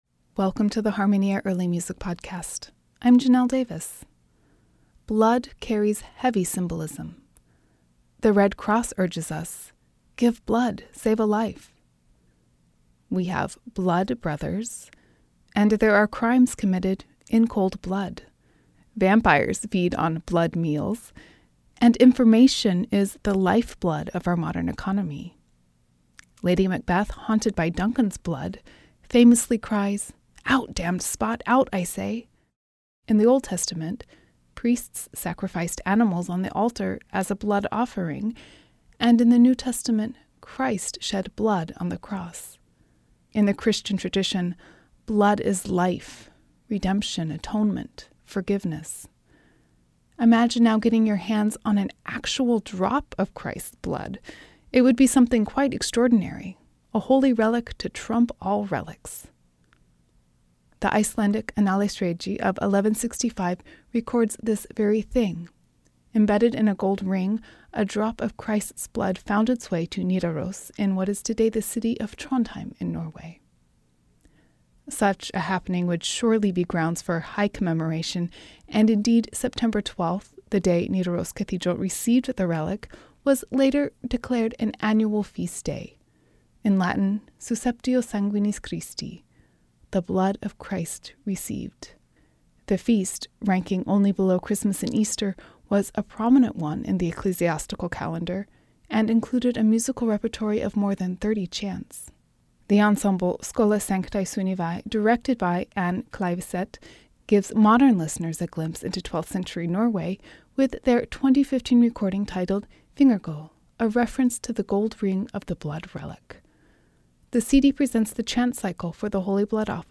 Susceptio sanguinis Christi-the Holy Blood Office Based almost entirely on melodies from older Offices, the music for the Holy Blood Office was probably written down between 1250 and 1275.